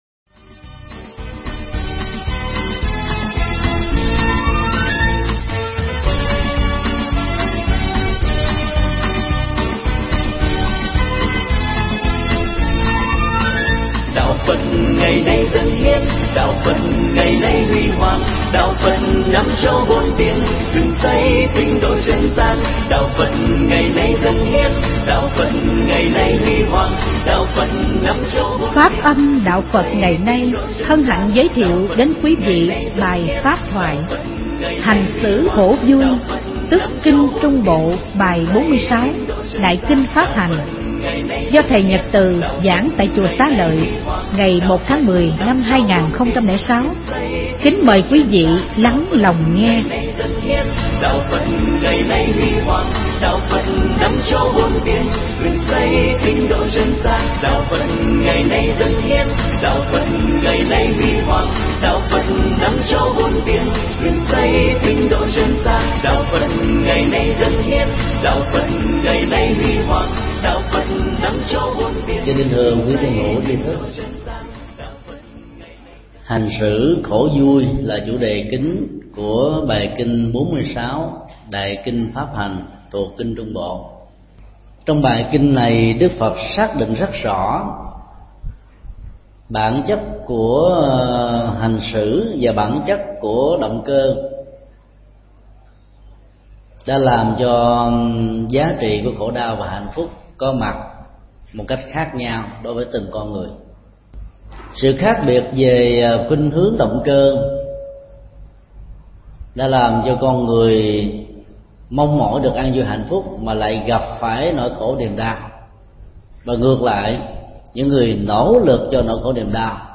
Mp3 Pháp thoại Kinh Trung Bộ 046: Hành xử khổ vui
tại Chùa Xá Lợi, ngày 1 tháng 10 năm 2006.